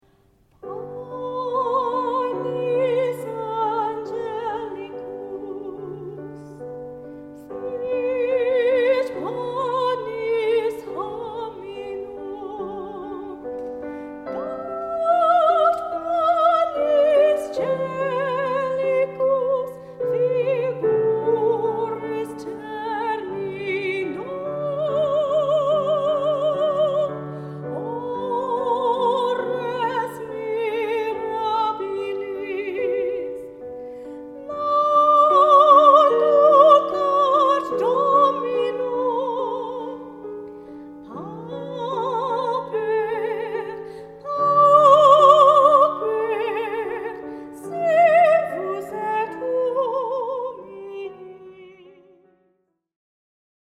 Waterford Soprano